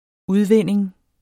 Udtale [ -ˌvenˀeŋ ]